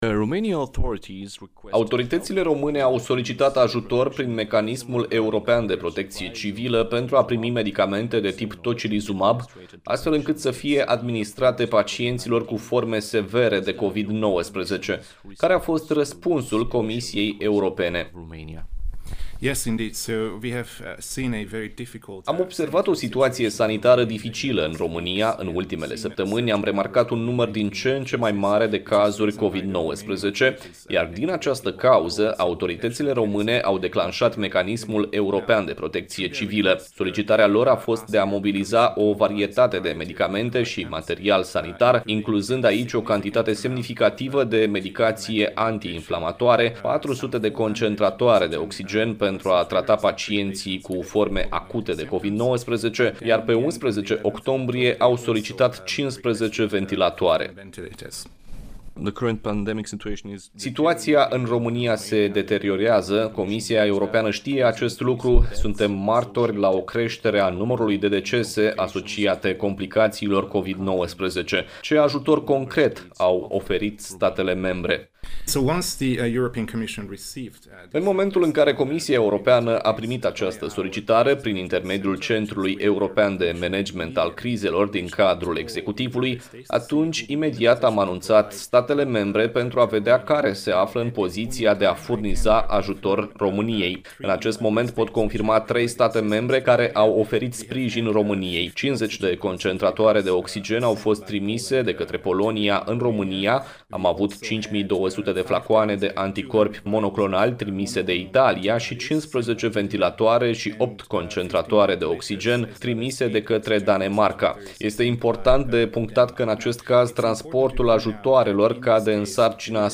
Interviu tradus: